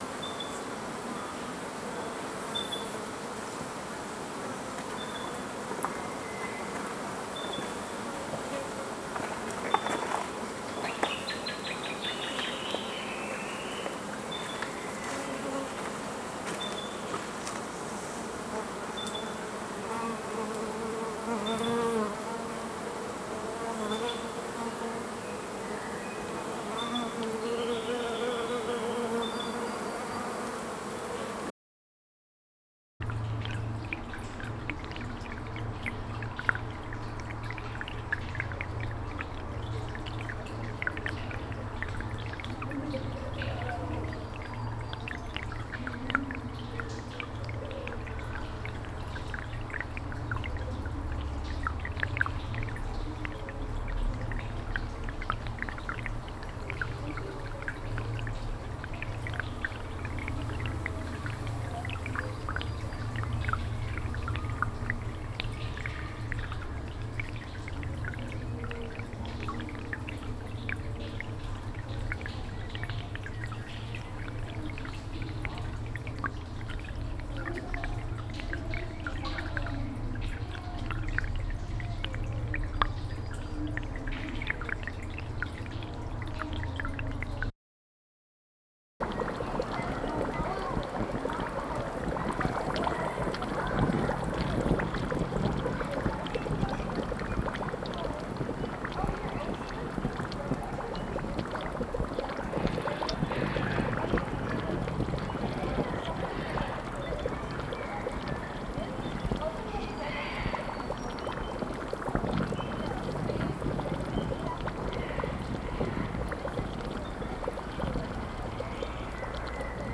Recorded along Wild Cat Creek and Spring Creek, Hepburn Springs on Jaara Country